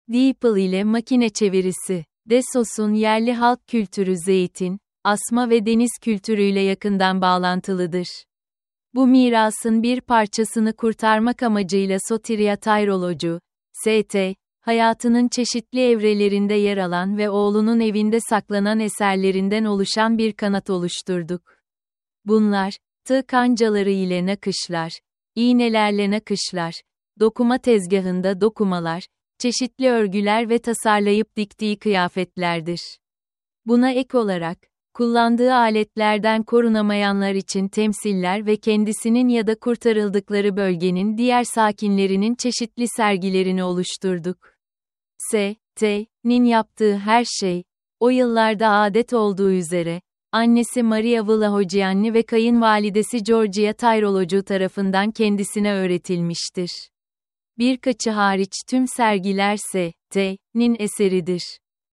Sesli rehberli tur